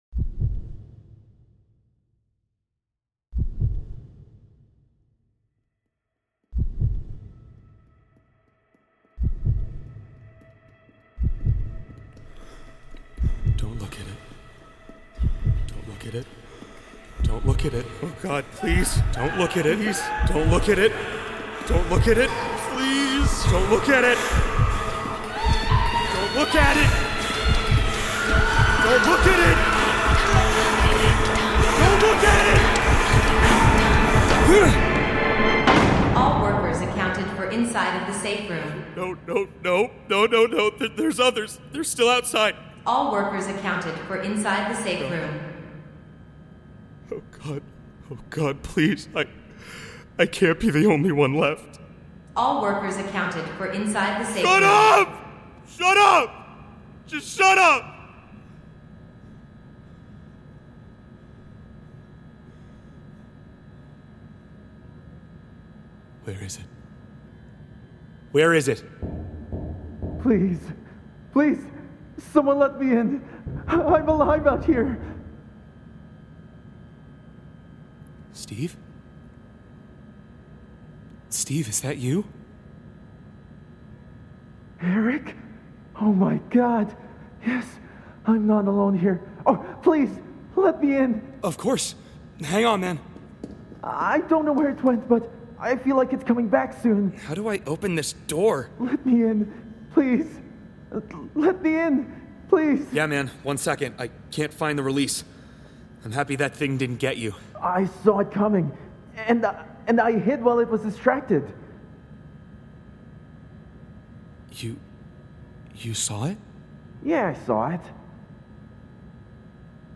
Look At Me Thu, 13 Mar 2025 03:31:19 GMT Hello! I made a one shot audio drama proof of concept for myself.
I made this because I want to make stories that are shown(heard) and not Narratored(told) out but is still understandable.